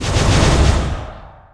effect_magic_hit_0008.wav